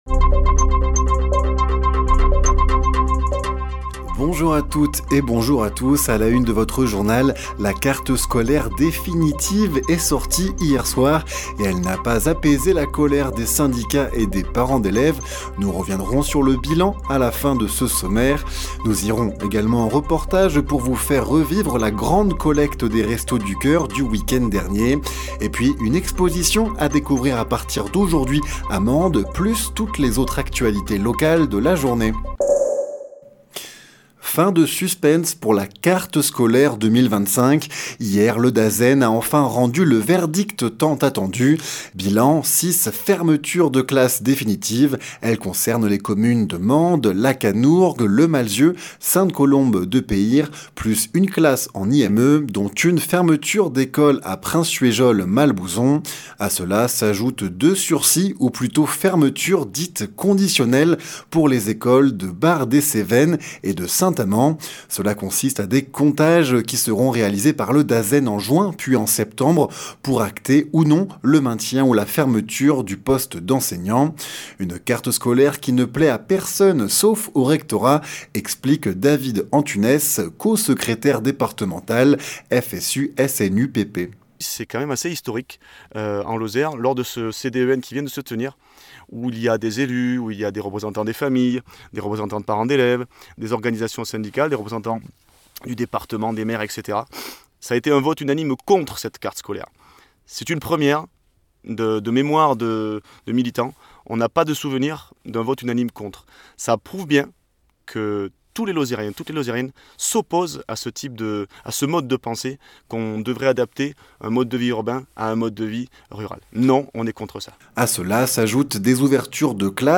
Les informations locales